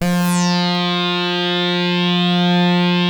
53-SAWRESWET.wav